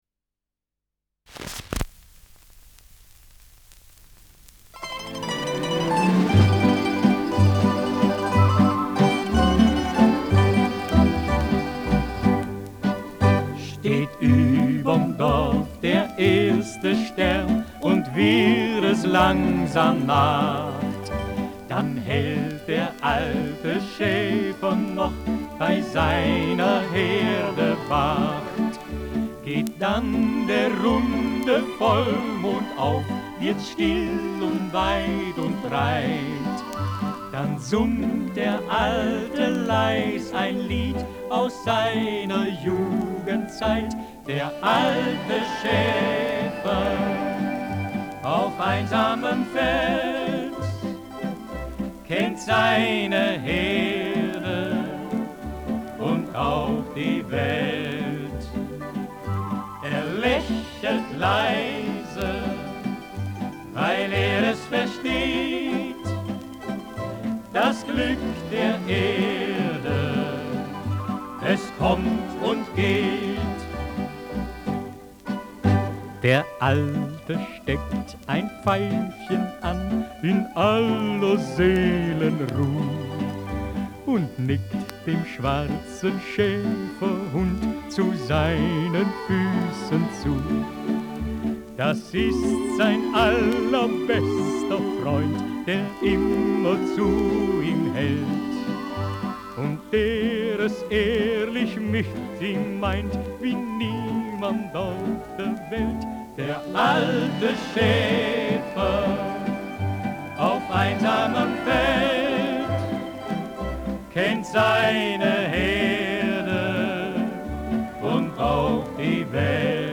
Walzerlied
Schellackplatte
Leichtes Grundrauschen : Vereinzelt leichtes Knacken
mit Chor und Orchester